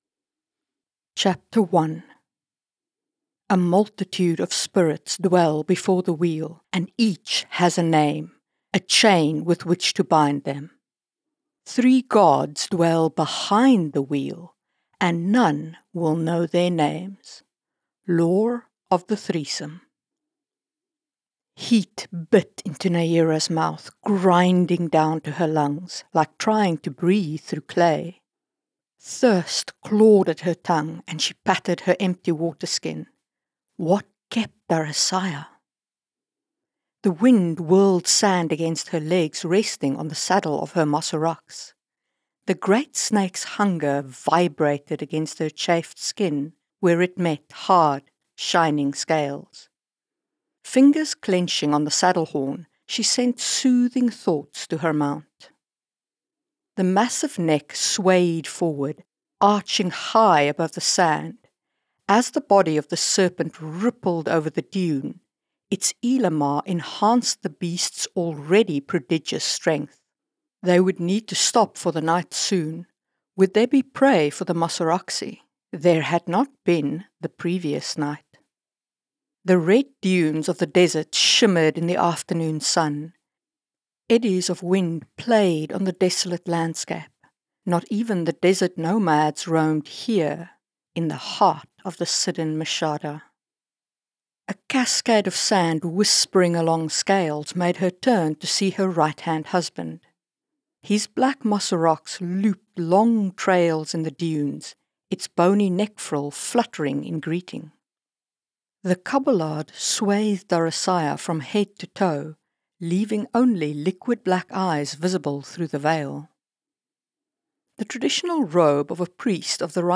Ducal Heir (Audio Book) | Marie Mullany